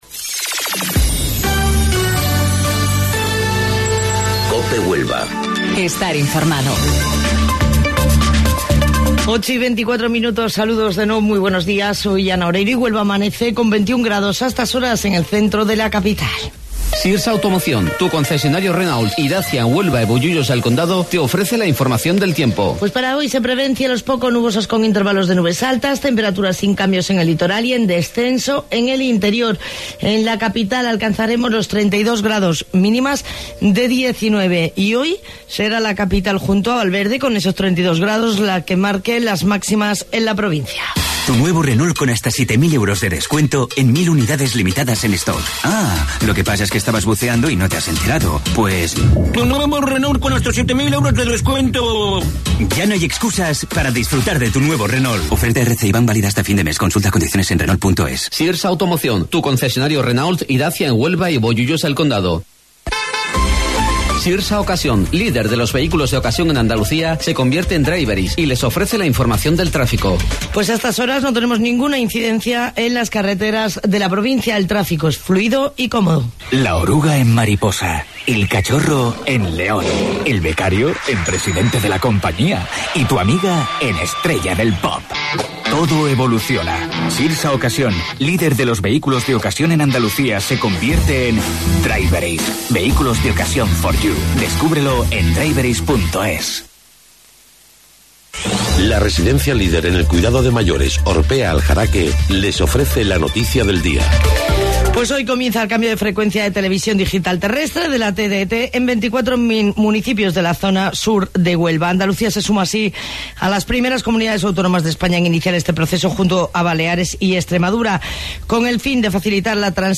AUDIO: Informativo Local 08:25 del 26 de Julio